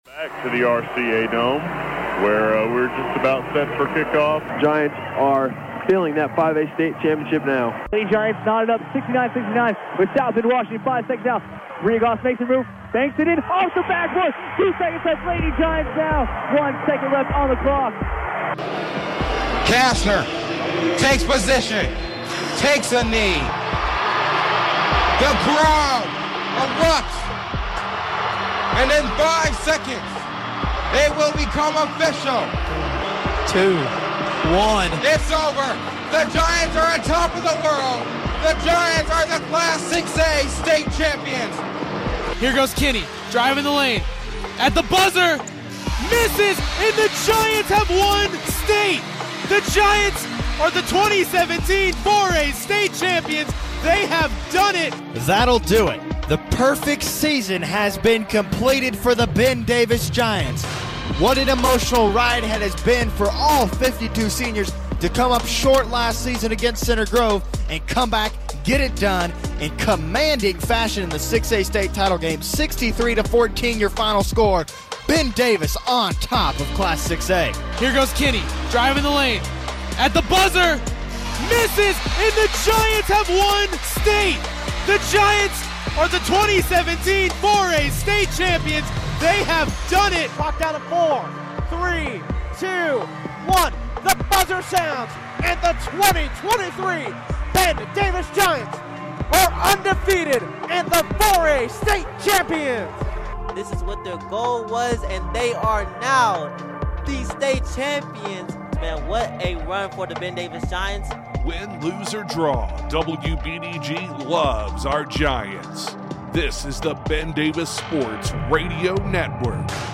Something special for our 60th Anniversary...a look back at some of our state broadcasts. We didn't have 'em all, but these are a few times where the Giants stood on top of the world!